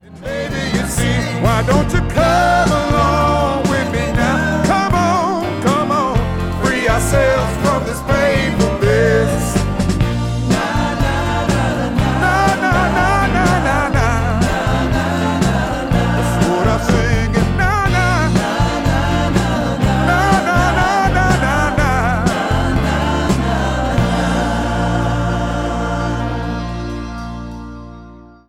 the rich voice
lead vocals